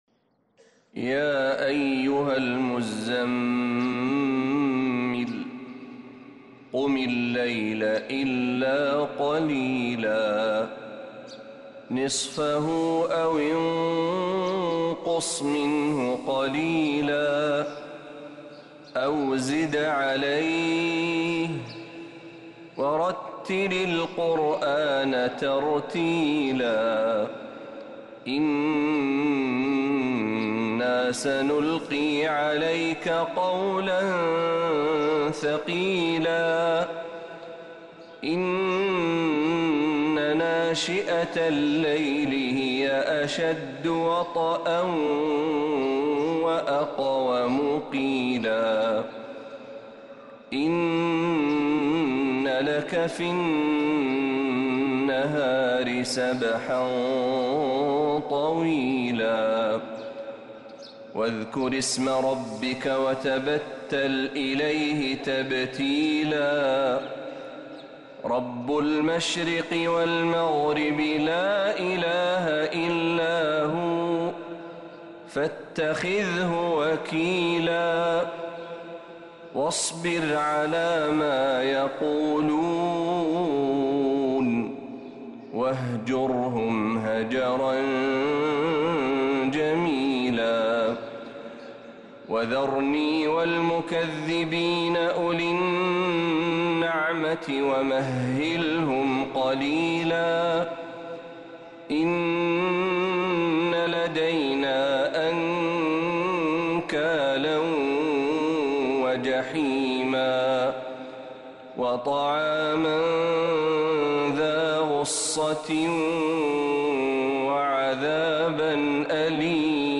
سورة المزمل كاملة من الحرم النبوي